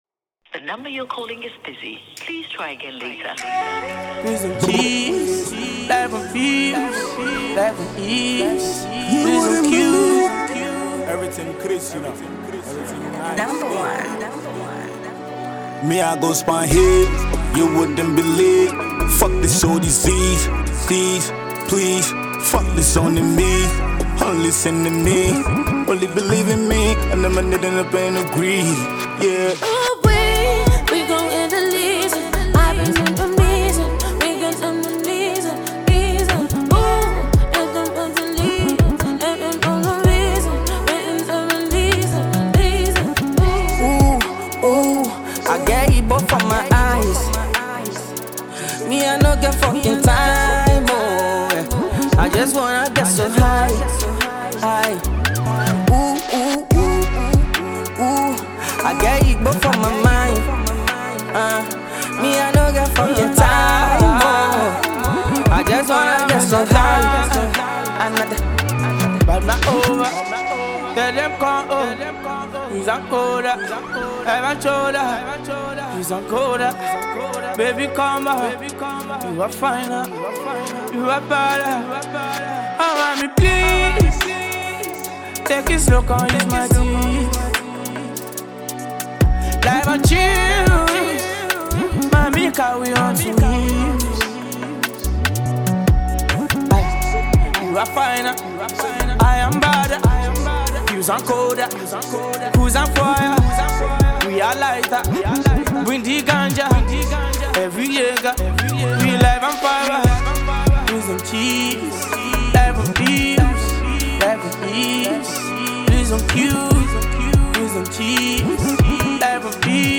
Najia Afrobeat
Enjoy this freestyle song and add it to your tracklist.